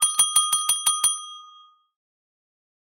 deal_closed.8dam2GkI.wav